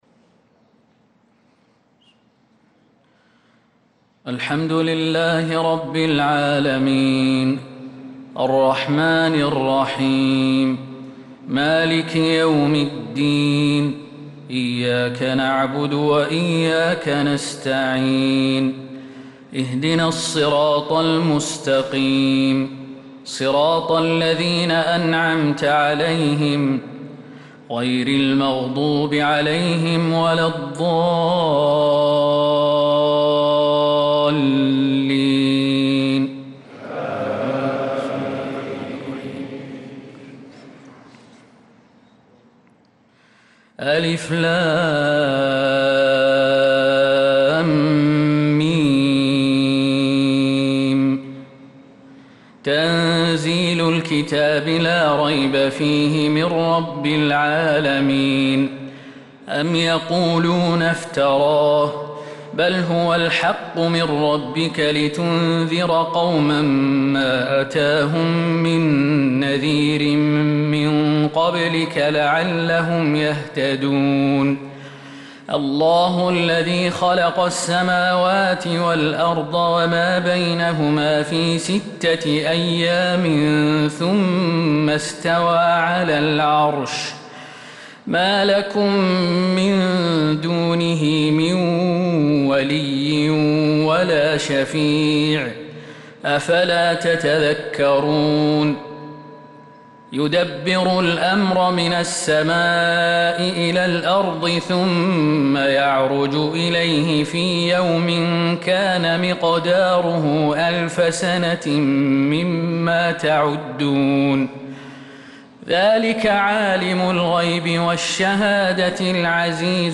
صلاة الفجر للقارئ خالد المهنا 8 ذو الحجة 1445 هـ